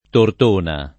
Tortona [ tort 1 na ]